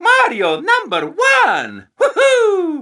Mario Number One Whoohoo Sound Effect Free Download